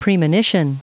Prononciation du mot premonition en anglais (fichier audio)